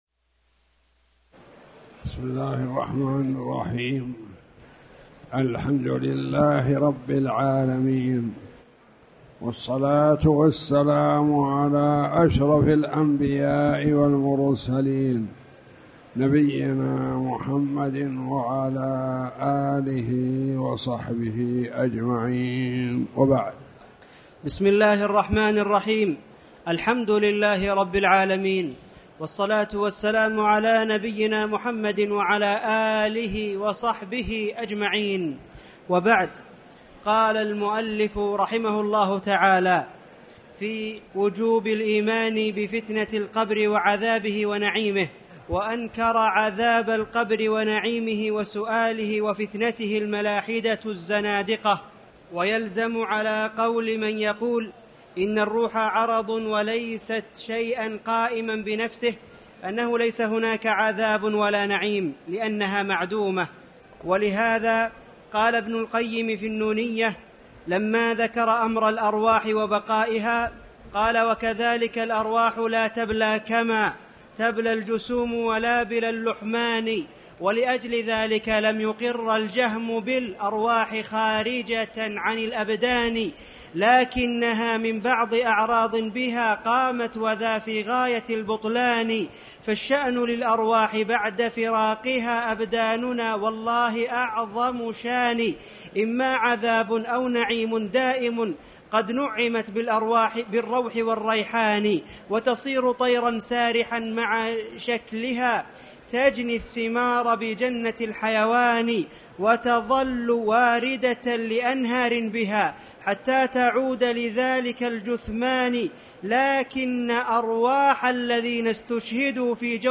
تاريخ النشر ٢٣ ذو القعدة ١٤٣٨ هـ المكان: المسجد الحرام الشيخ